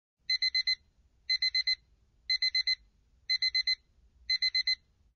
TraditionalAlarm.ogg